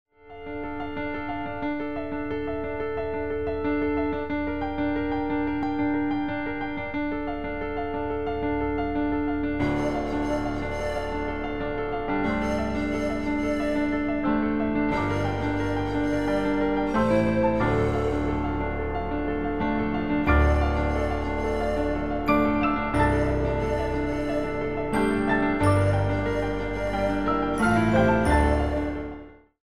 13 InstrumentalCompositions expressing various moods.